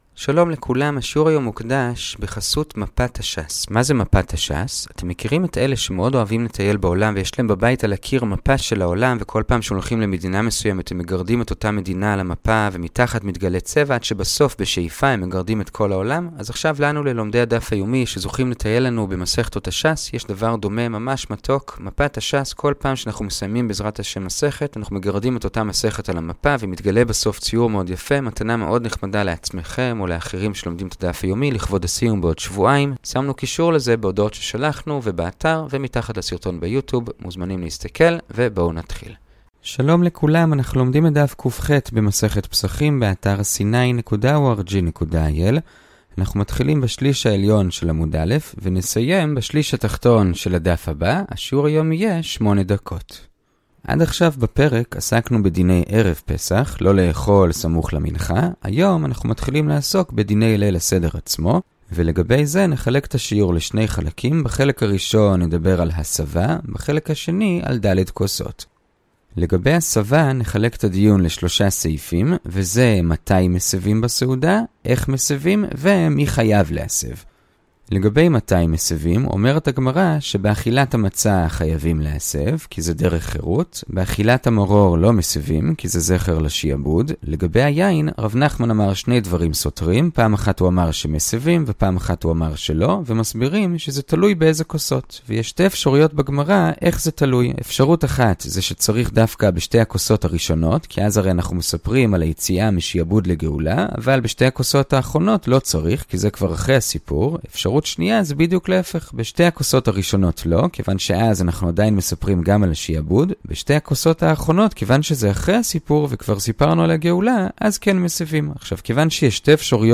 הדף היומי - פסחים קח - הדף היומי ב15 דקות - שיעורי דף יומי קצרים בגמרא